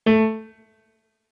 MIDI-Synthesizer/Project/Piano/37.ogg at 51c16a17ac42a0203ee77c8c68e83996ce3f6132